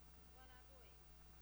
本來寫作「龜孔」，孔就是洞的意思，指以前有個烏龜洞 在日本時代初期,官方文書寫成「龜空」,後來有人為了使「龜孔」的「孔」一字更像台語 故在字旁另加一「口」，（一說是為了避諱在「孔」子前面加龜字好像冒犯孔子） : 八里的挖子尾是唸ㄨㄚ ㄌㄚ bue uat4-a2-bue2 (uat的t和後方的a結合會念成ua-la)